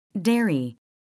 미국 [dέəri] 듣기